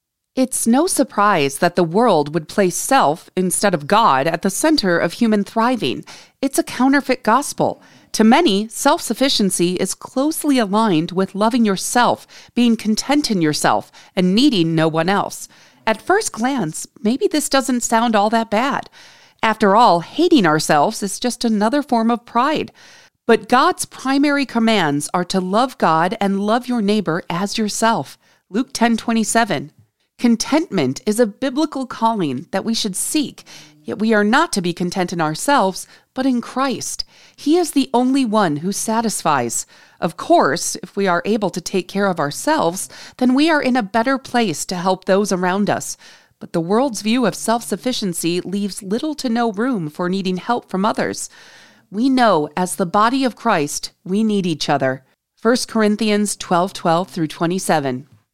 Female
I've got a lower register female voice but I'm a soprano singer, so my range is expansive.
Audiobooks
Christian Audiobook Reading
All our voice actors have professional broadcast quality recording studios.